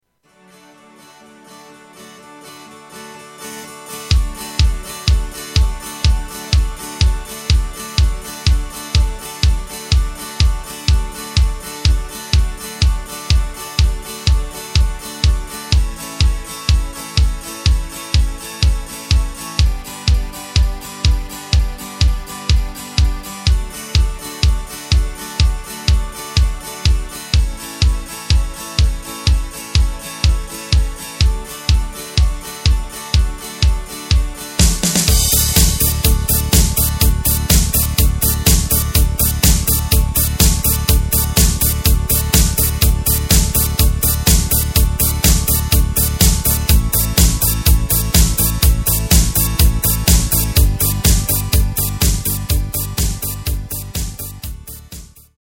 Tempo:         124.00
Tonart:            D
Ostrock-Hymne aus dem Jahr 2019!
Playback mp3 Demo